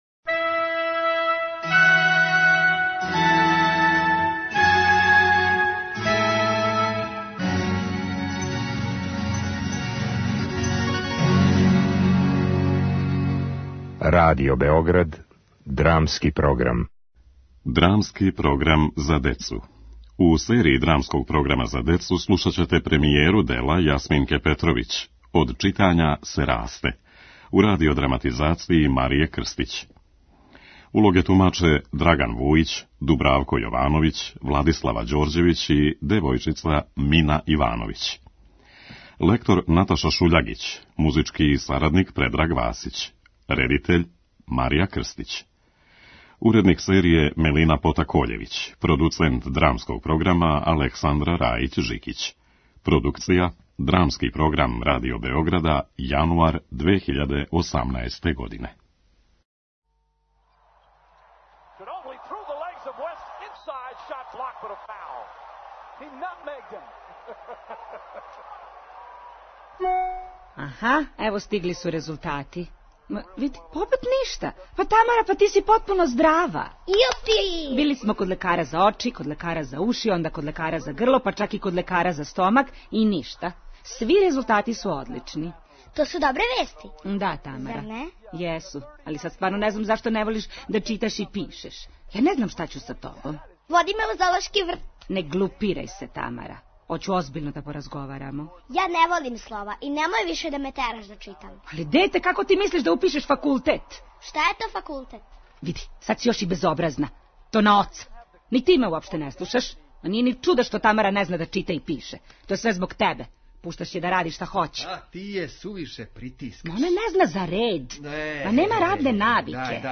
Dramski program za decu: Jasminka Petrović „Od čitanja se raste“, premijera